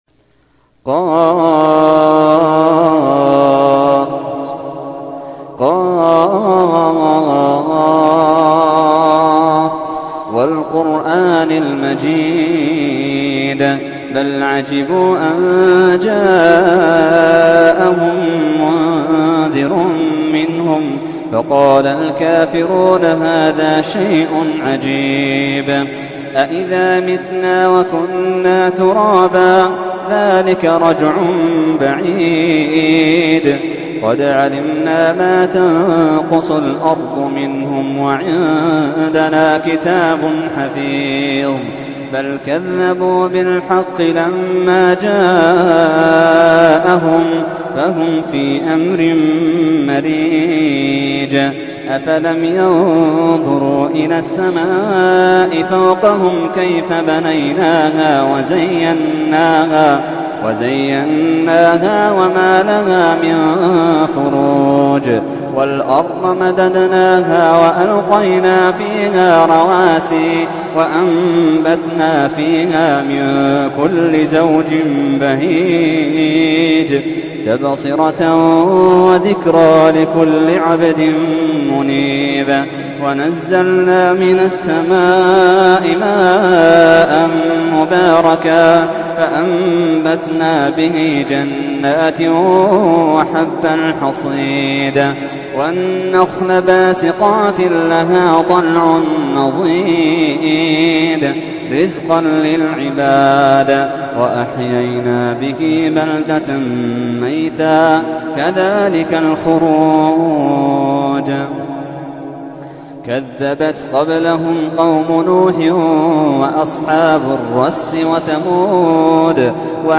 تحميل : 50. سورة ق / القارئ محمد اللحيدان / القرآن الكريم / موقع يا حسين